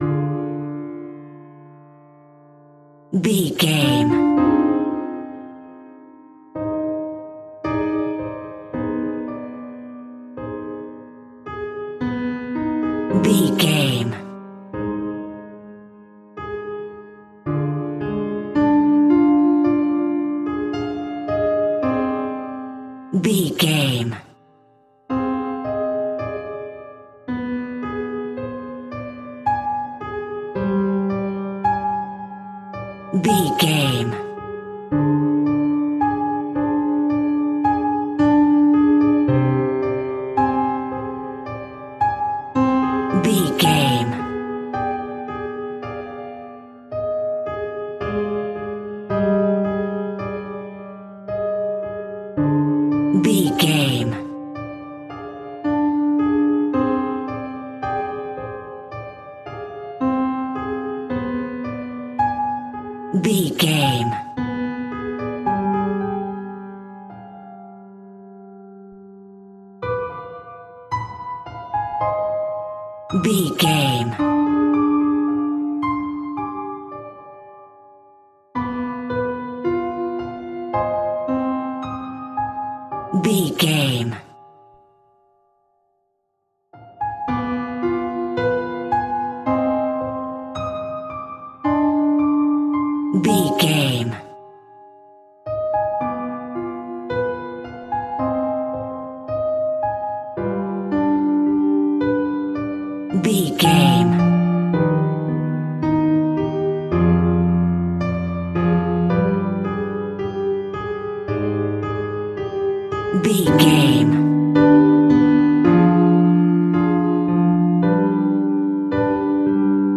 In-crescendo
Aeolian/Minor
tension
ominous
dark
suspense
eerie
creepy